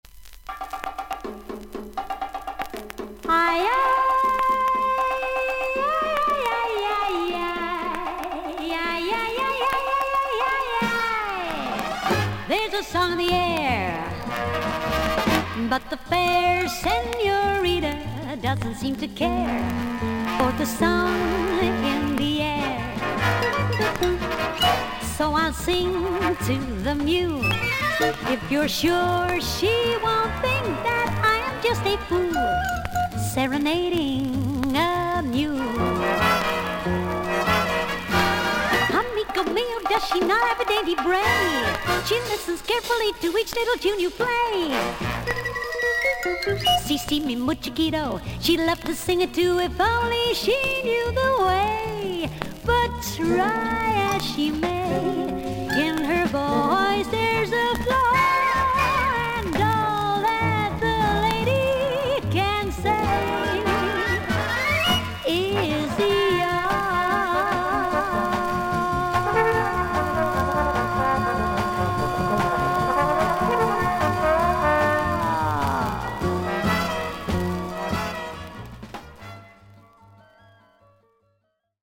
イギリス盤 / 12インチ LP レコード / モノラル盤
試聴はそれらのキズのなかでノイズが目立つA1の部分です。
イギリスの女性ジャズ・シンガー。